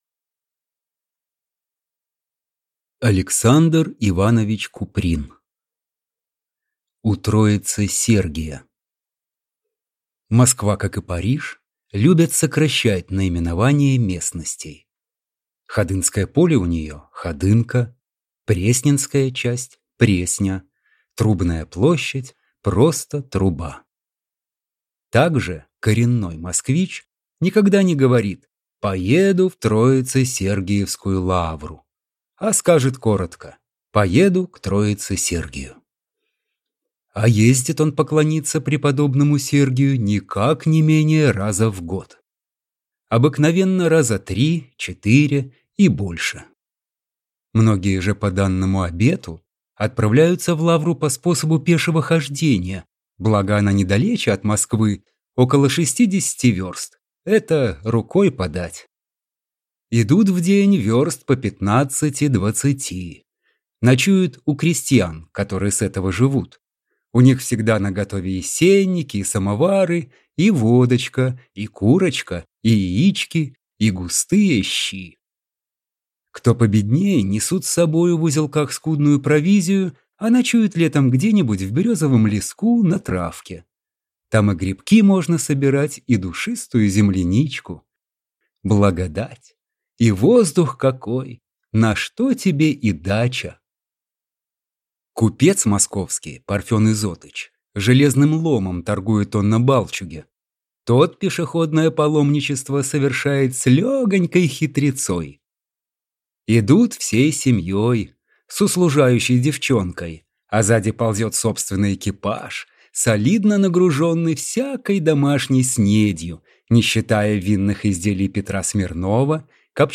Аудиокнига У Троице-Сергия | Библиотека аудиокниг